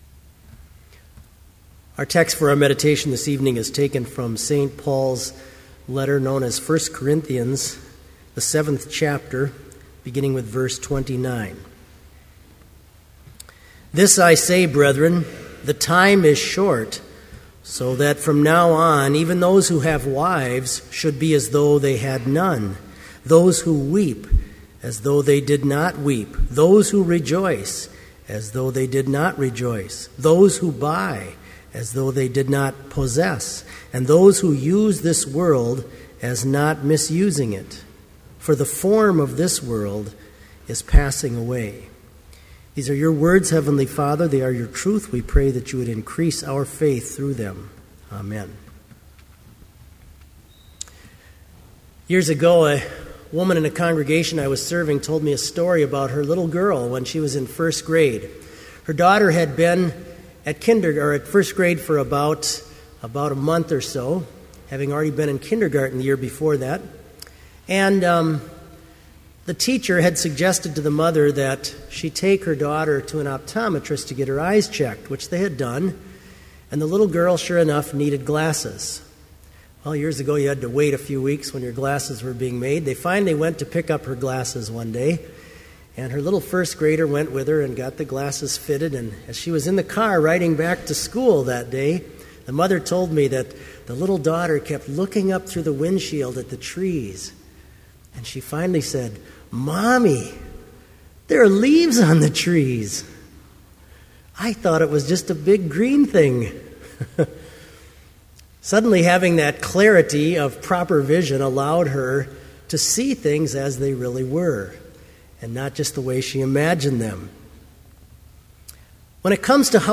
Complete Service
• Prelude
• Homily
• Choir Canticle: Magnificat in D Luke1:46-55-J. Pachelbel, 1653-1706
• Postlude
This Vespers Service was held in Trinity Chapel at Bethany Lutheran College on Wednesday, September 19, 2012, at 5:30 p.m. Page and hymn numbers are from the Evangelical Lutheran Hymnary.